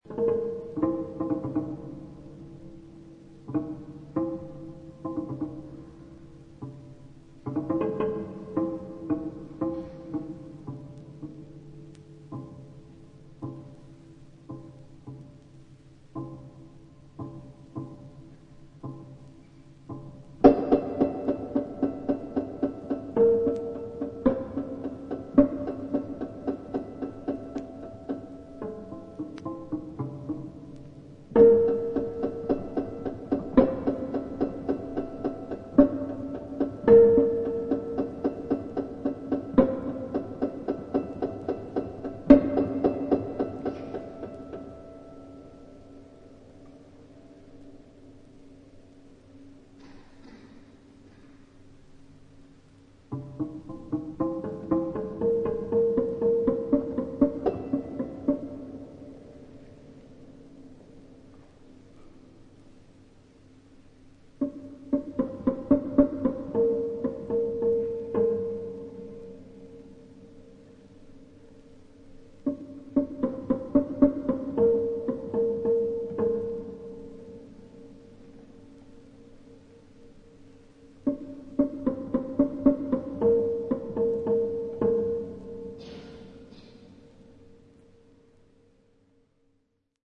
1975年にドイツ・シュトゥットガルトで行ったライヴ・レコーディング盤
深みのある空間的なエフェクトが施されたアコースティックな響きが心地よいアジアの打楽器によるメディテーティブな楽曲